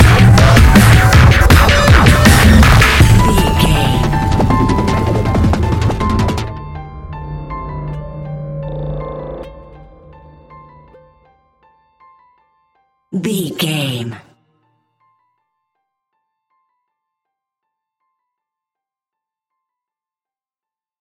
Dubstep Glitch Terror Stinger.
Aeolian/Minor
Fast
aggressive
dark
hypnotic
industrial
heavy
drum machine
synthesiser
piano
breakbeat
energetic
synth bass